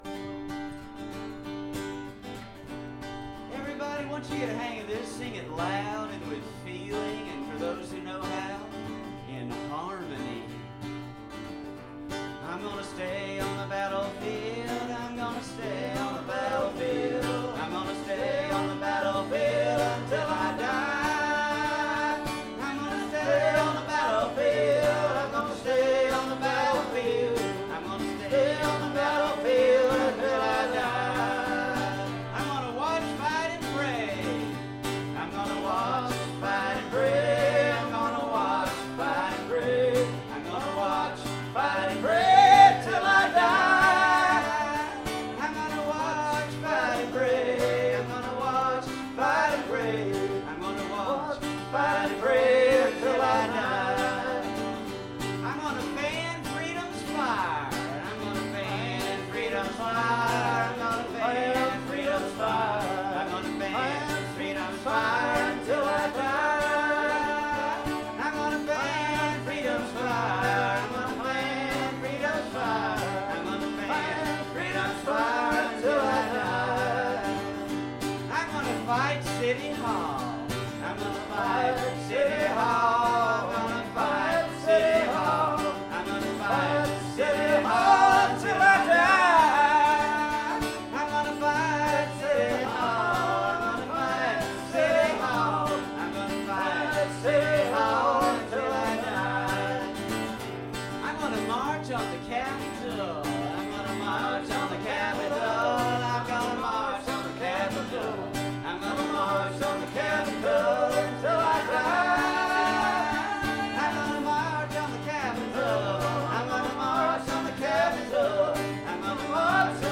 Traditional hymn